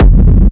• Industrial Techno Kick oneshot SC - F.wav
Nicely shaped, crispy and reverbed industrial techno kick, used for hard techno, peak time techno and other hard related genres.
Industrial_Techno_Kick_oneshot_SC_-_F__ix6.wav